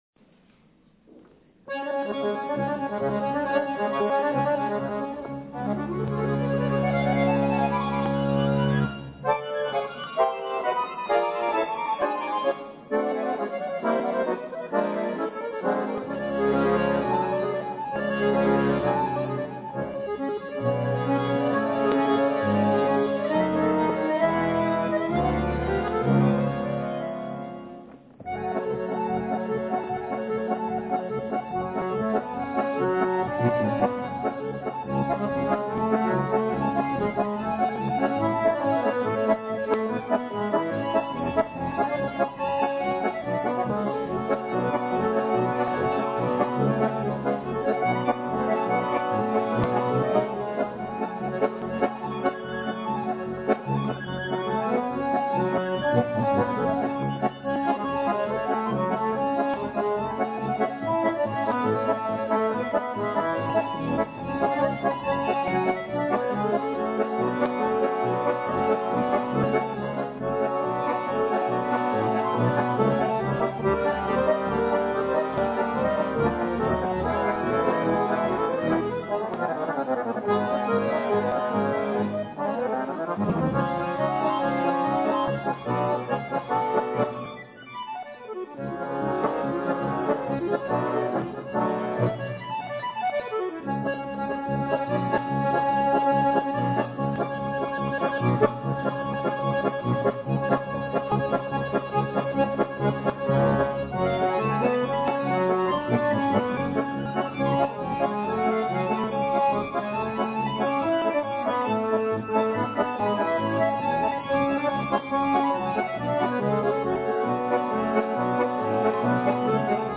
Escuela de Acorde�n Javier Torres